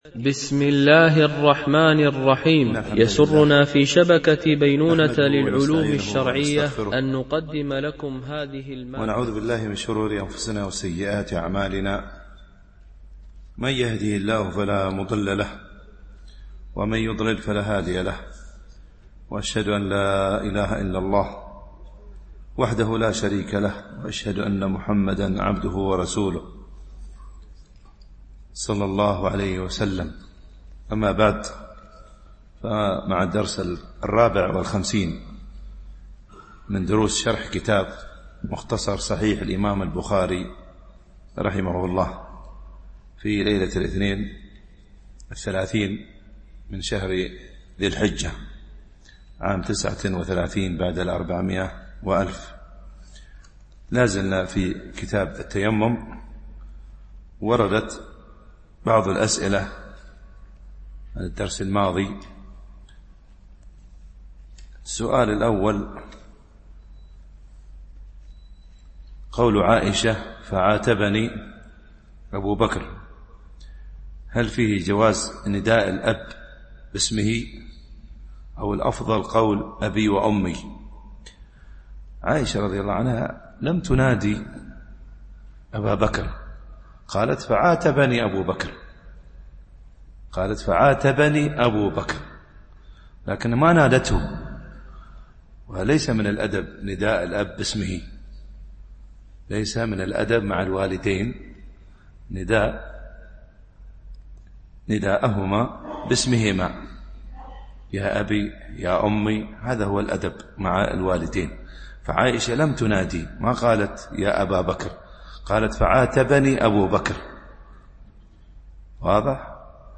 شرح مختصر صحيح البخاري ـ الدرس 54 ( الحديث 227 )